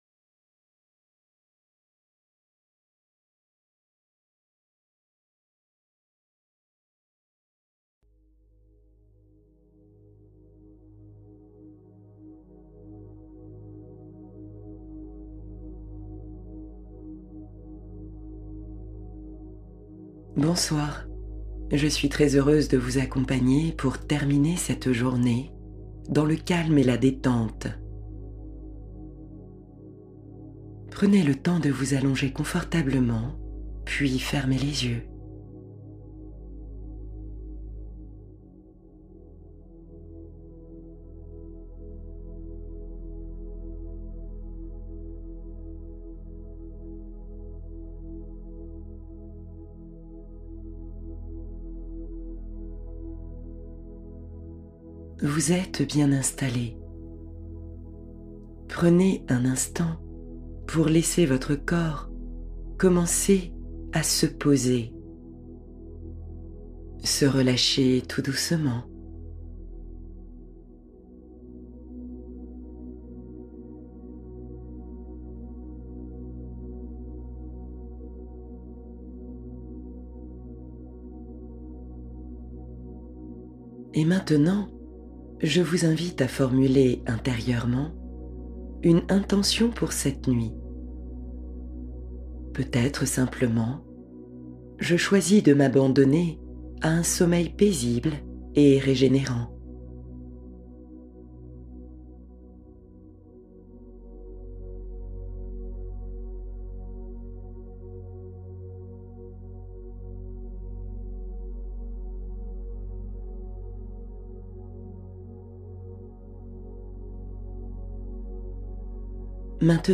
Allègement intérieur durable — Méditation pour libérer le poids émotionnel